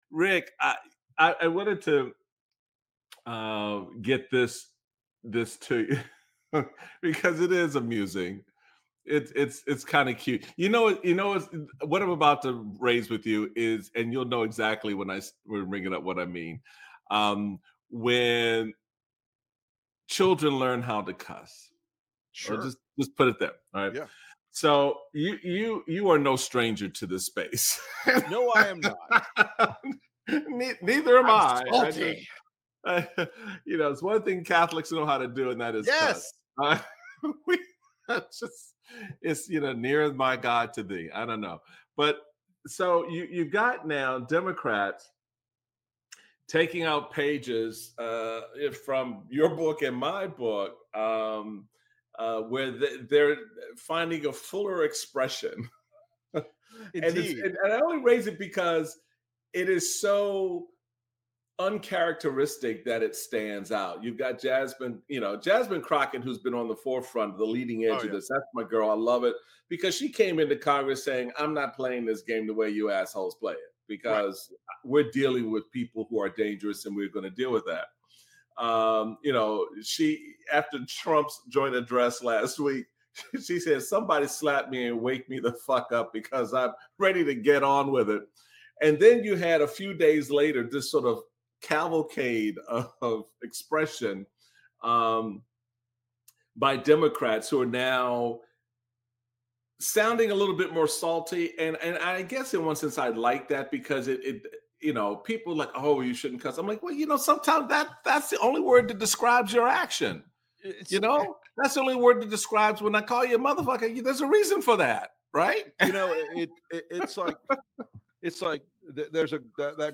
Michael Steele speaks with Republican strategist, Lincoln Project co-founder and NYT best-selling author, Rick Wilson.